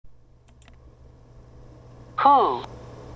hはローマ字のハ行の文字に使われているため、whiteを「ホワイト」と言ったり、whale(くじら) を「ホエール」と言ったりしますが、ネイティブの言うwhにhは存在しないと言えるくらい弱く、疑問詞シリーズwhat、who、which、 when、where・・・の中でもhの音が生かされているのはwhoだけで、他はほとんど無視です。